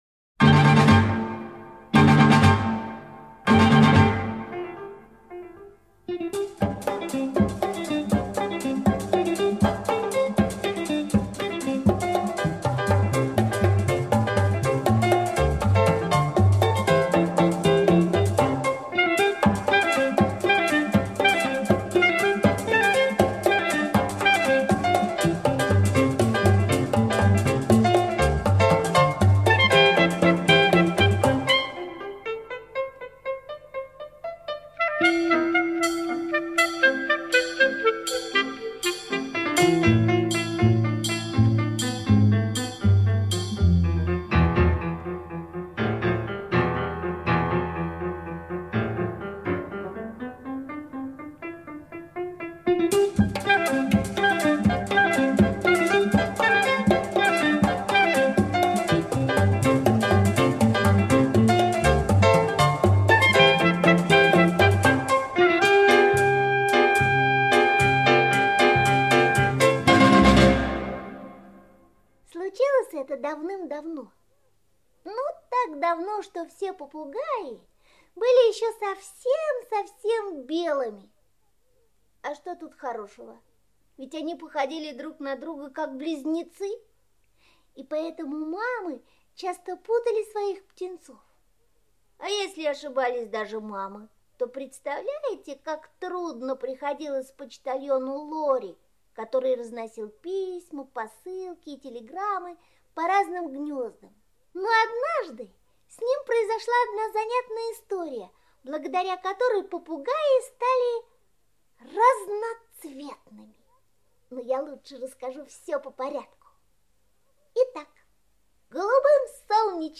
Слушайте Как попугай Лори стал разноцветным - аудиосказка Пляцковского М.С. Историю, после которой все попугаи стали разноцветными.